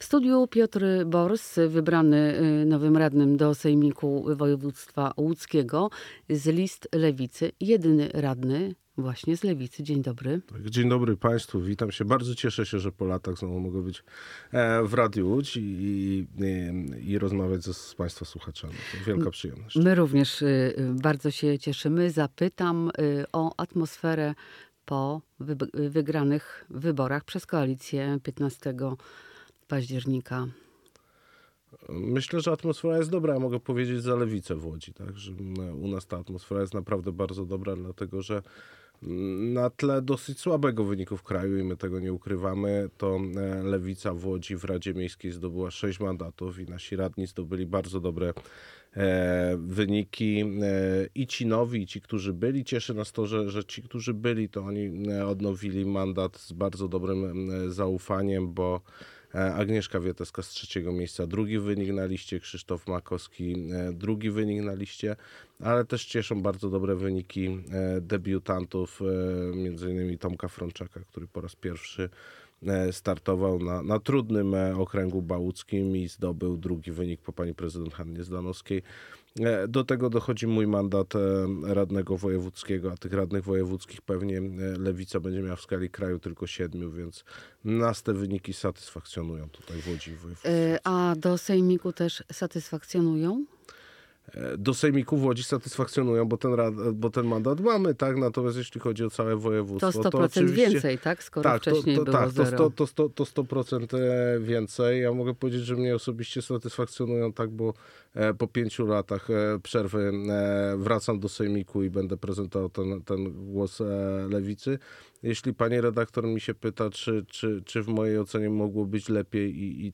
Piotr Bors w porannej Rozmowie Dnia przyznał, że ze strony PiS-u były już próby nawiązania z nim kontaktu.